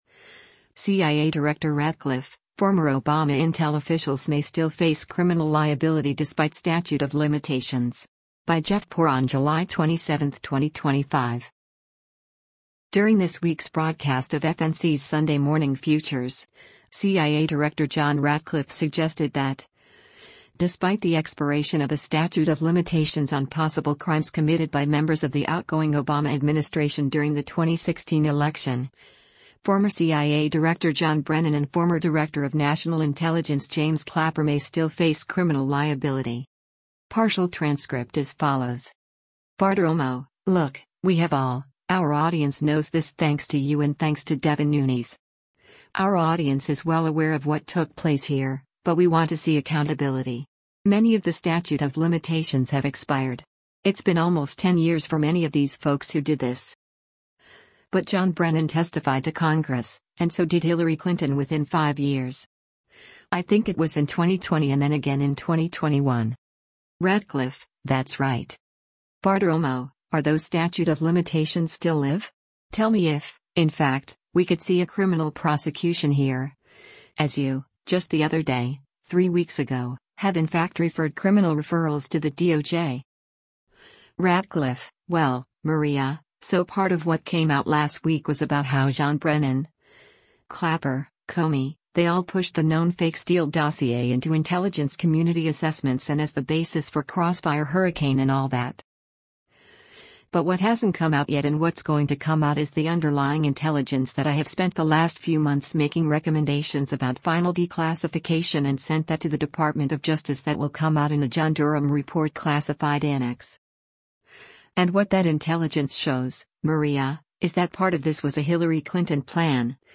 During this week’s broadcast of FNC’s “Sunday Morning Futures,” CIA Director John Ratcliffe suggested that, despite the expiration of a statute of limitations on possible crimes committed by members of the outgoing Obama administration during the 2016 election, former CIA Director John Brennan and former Director of National Intelligence James Clapper may still face criminal liability.